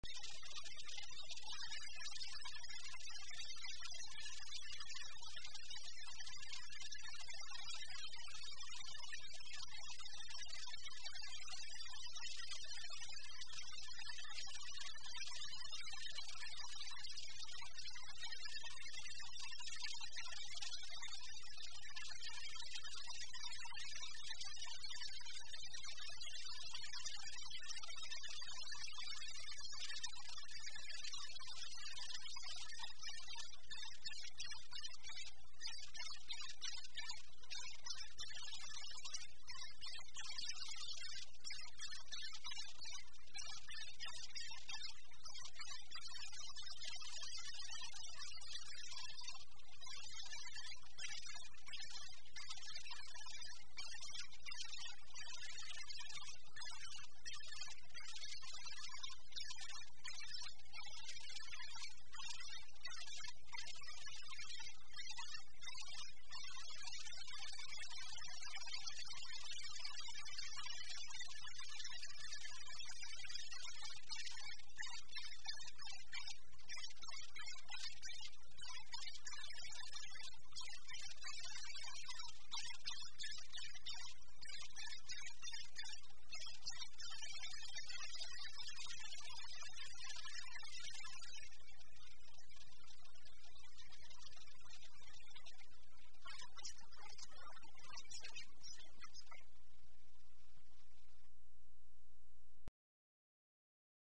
ich hör den Kettendrucker rasseln.